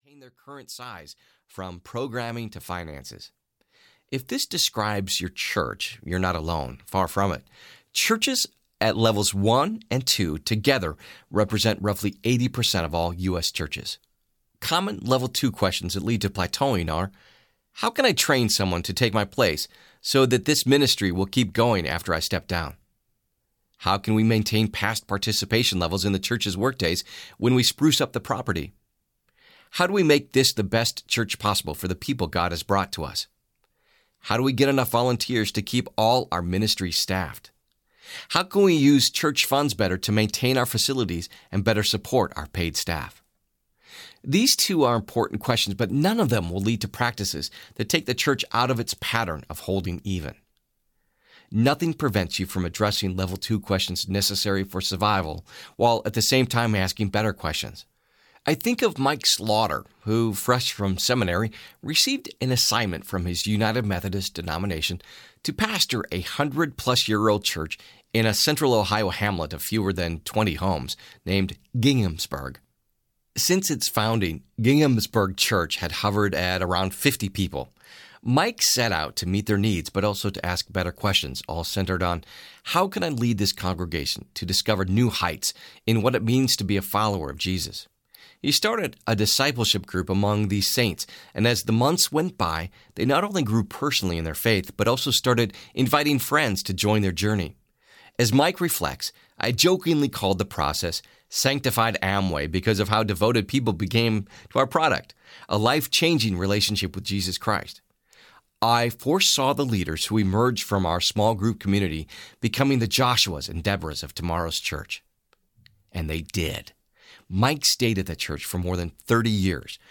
Hero Maker Audiobook
6.3 Hrs. – Unabridged